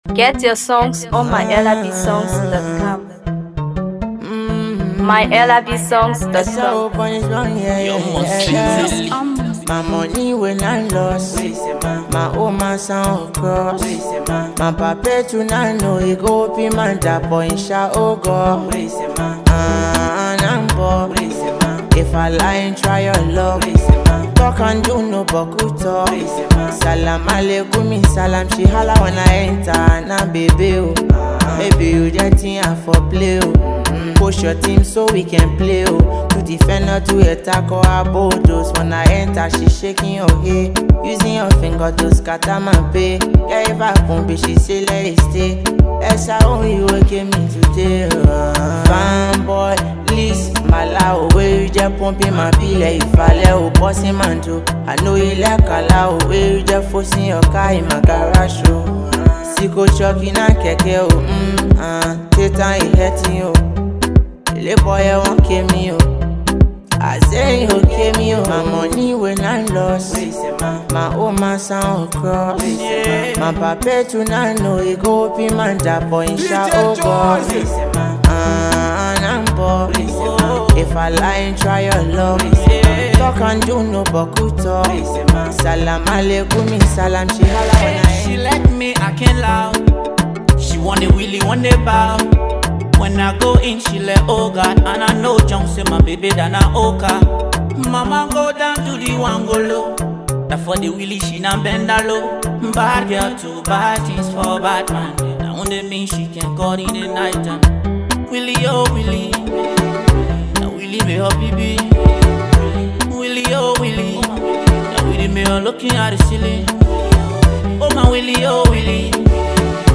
Afro PopMusic
electrifying beats, smooth vocals